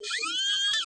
SERVO SE04.wav